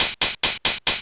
TAMB LOOP1-L.wav